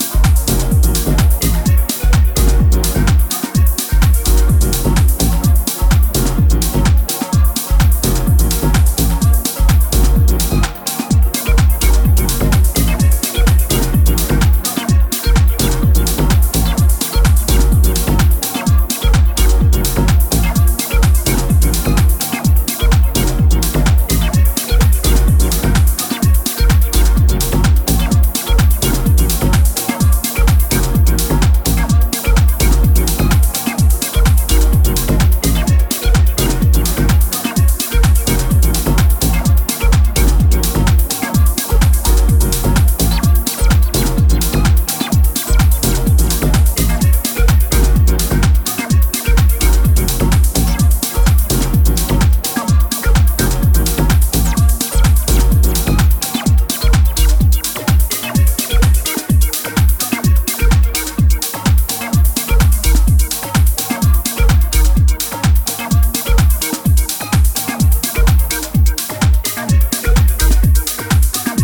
ジャンル(スタイル) DEEP HOUSE / TECH HOUSE